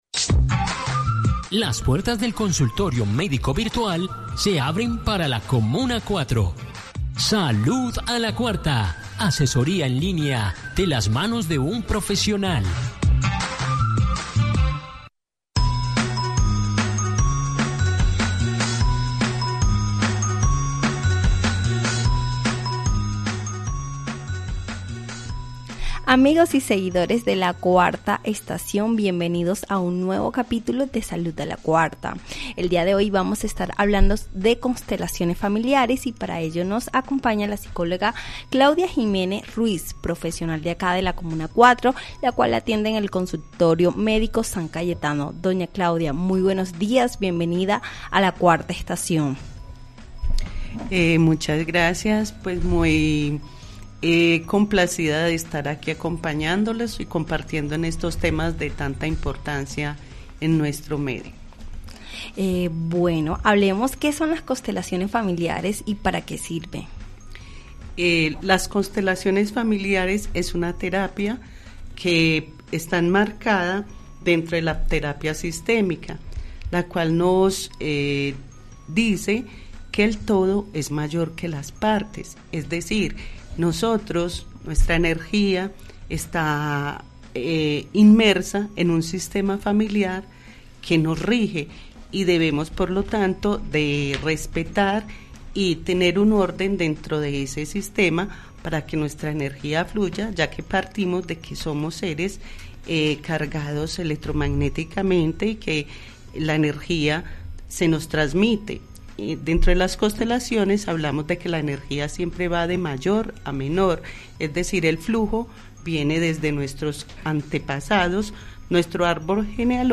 Si buscas respuestas y deseas profundizar en el fascinante mundo de la psicología familiar, te invitamos a escuchar a nuestra invitada experta...